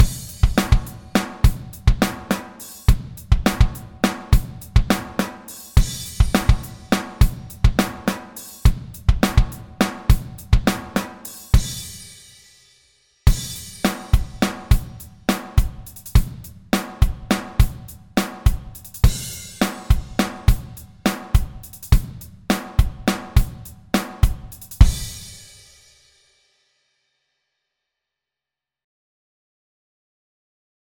Sound Sample: Rock Grooves in 5/8